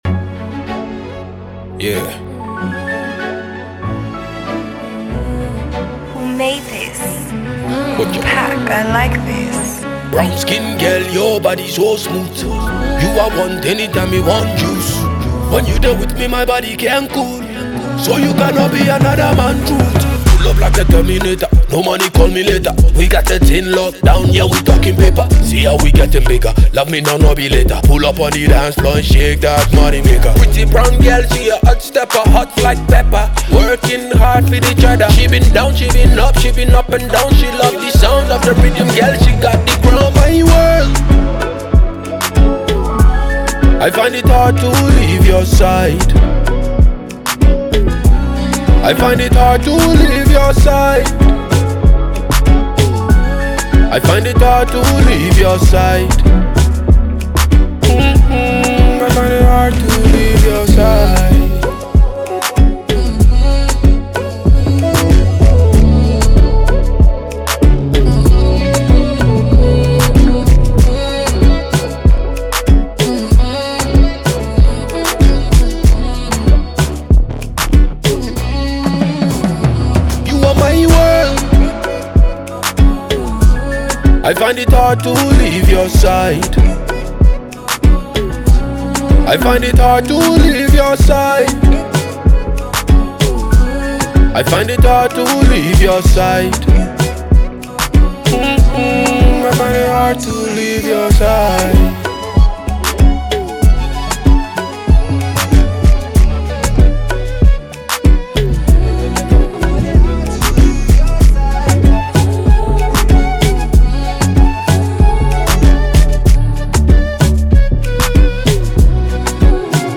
• Genre: Afrobeats / Emotional